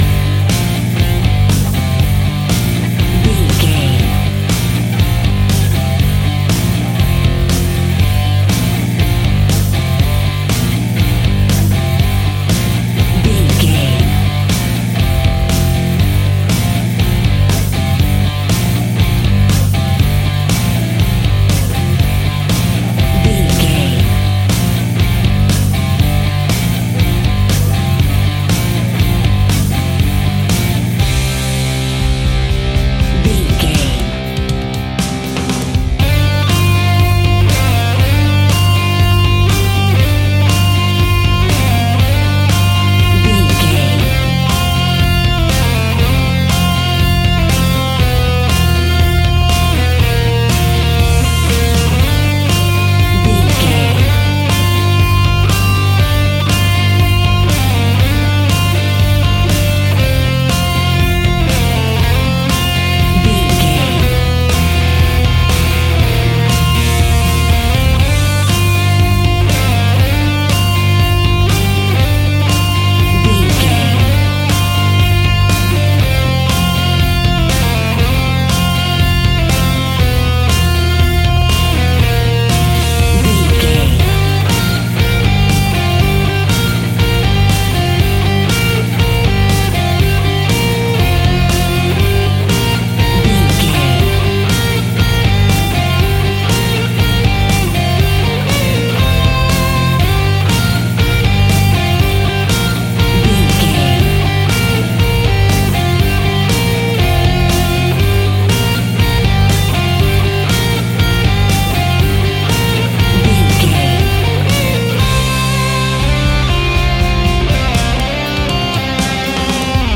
Epic / Action
Fast paced
Aeolian/Minor
hard rock
heavy metal
distortion
rock instrumentals
Rock Bass
heavy drums
distorted guitars
hammond organ